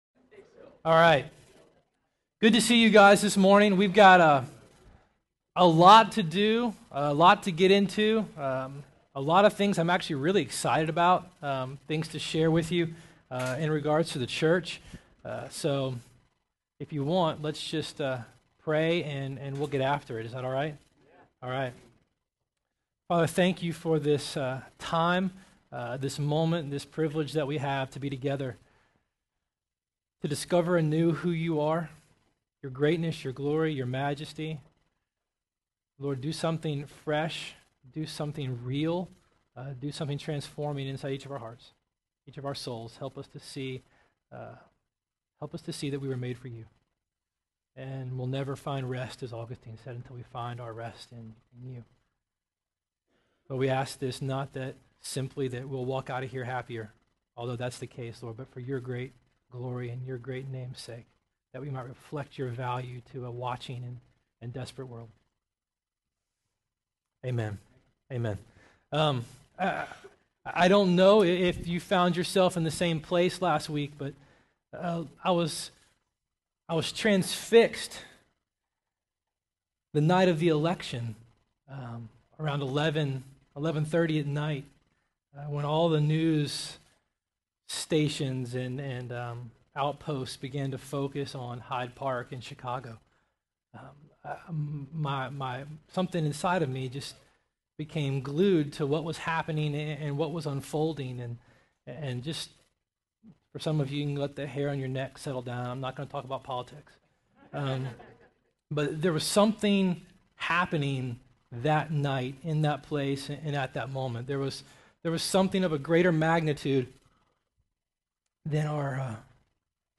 Sermon 11/16/08